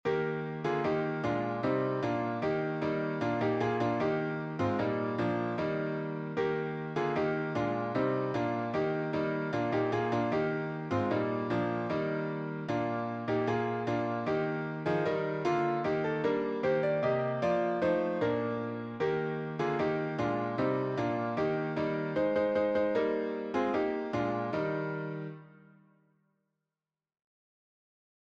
Old Welsh Carol